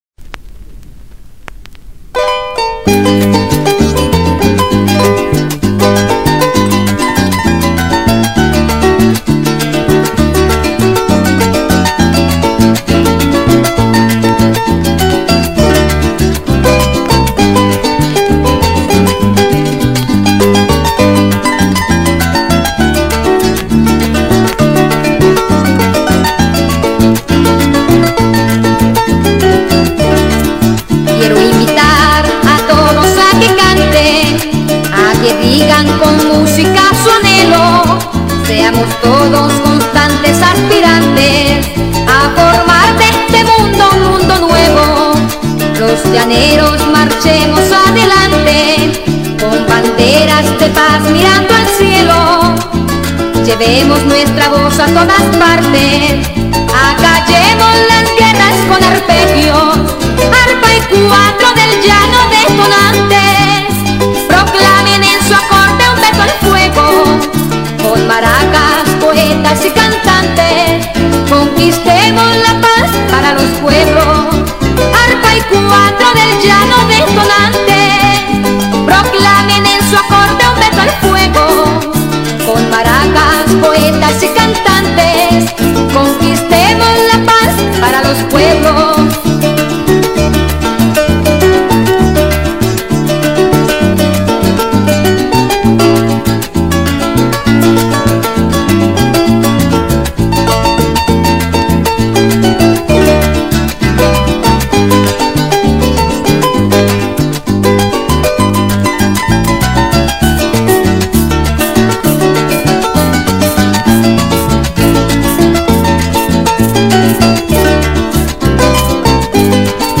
Ritmo: Pasaje.